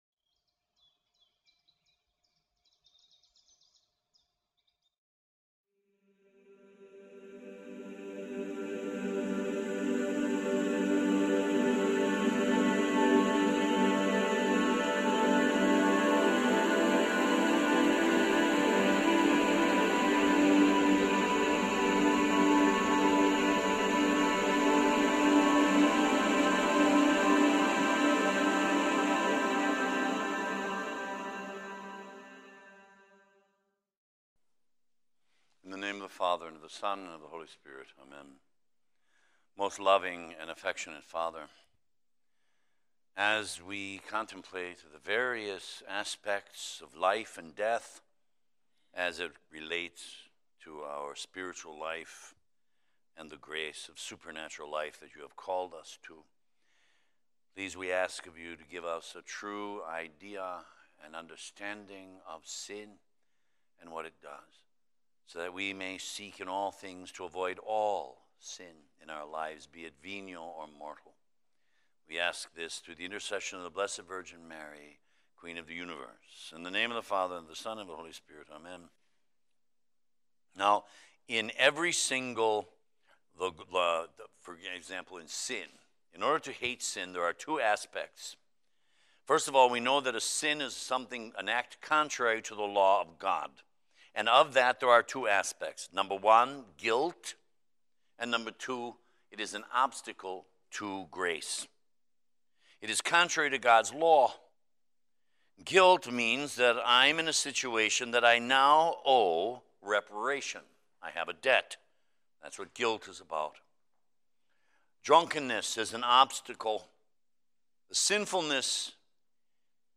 the fourth of twenty-five conferences for his Eight Day Ignatian Retreat. The subjects he covers are: Sin two aspects, guilt and obstacle to holiness, is a particular desire a temptation or an inspiration?, consolation v. desolation, confession, and death, its harshness but gives the blessing of a new beginning.